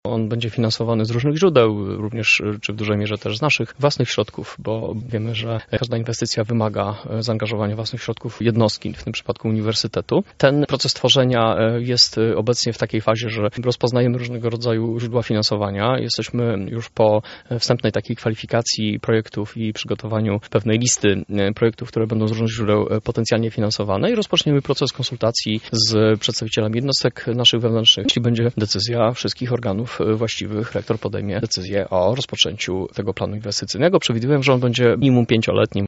[PORANNA ROZMOWA] Nowe perspektywy dla UMCS